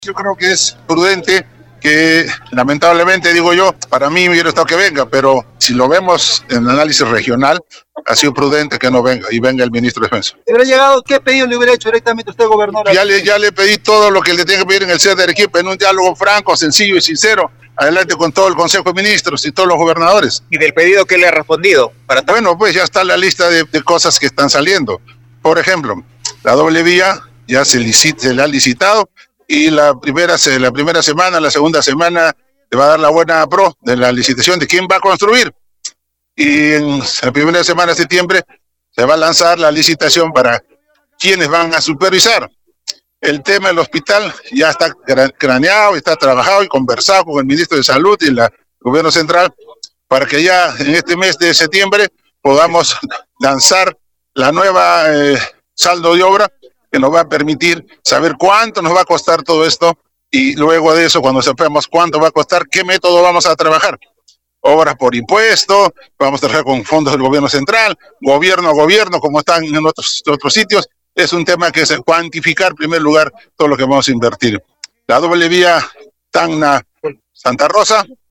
La autoridad regional declaró a medios al inicio de la Procesión de la Bandera realizada con motivo de los 95 años de reincorporación a la heredad nacional.